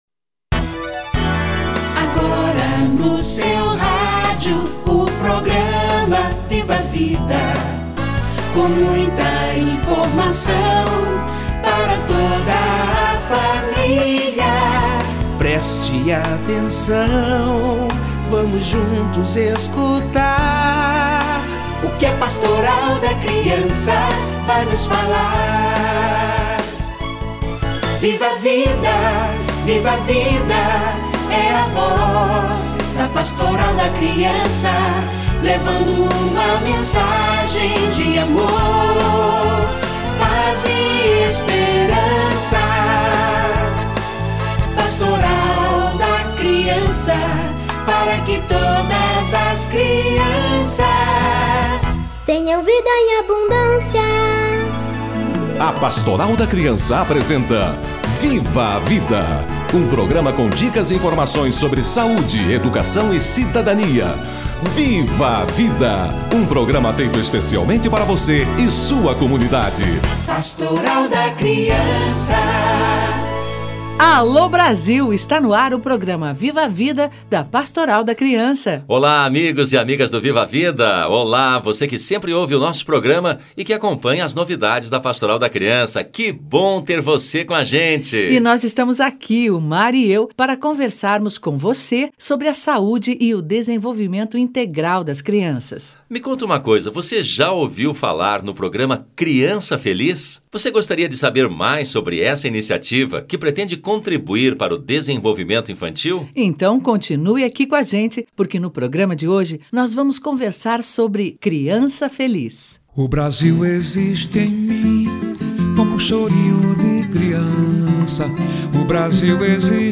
Criança Feliz - Entrevista